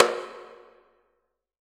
6MSURDO.wav